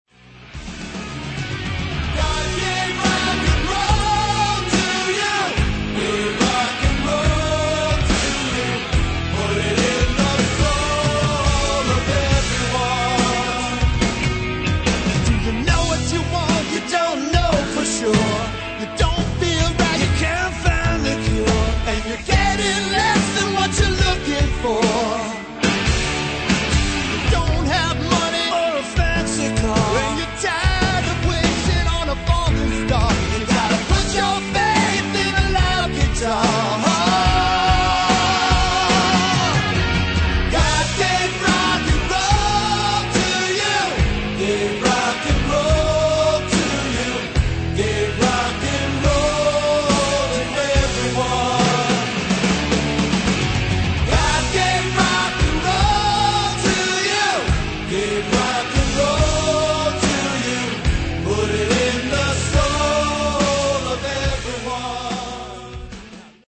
Mixed in Q Sound